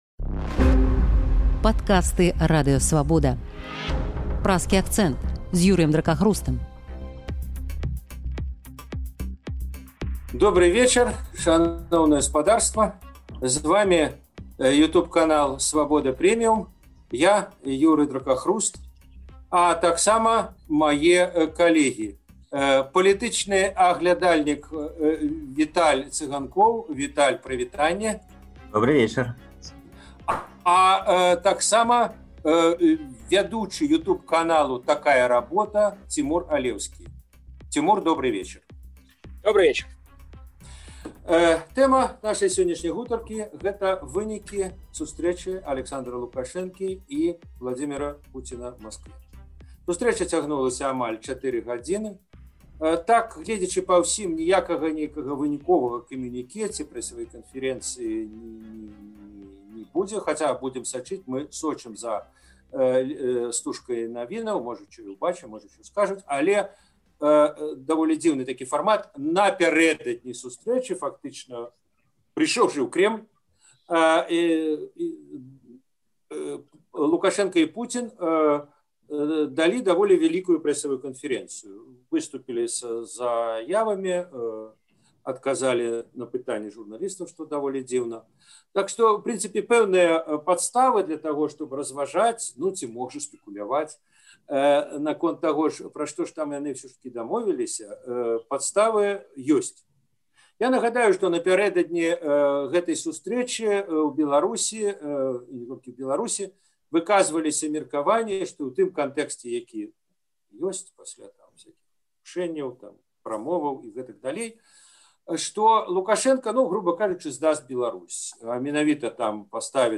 палітычны аналітык